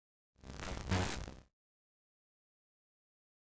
meleeattack-swoosh-magicaleffect-group01-lightning-02.ogg